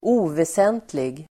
Uttal: [²'o:vesen:tlig]